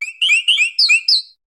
Cri de Plumeline Style Flamenco dans Pokémon HOME.
Cri_0741_Flamenco_HOME.ogg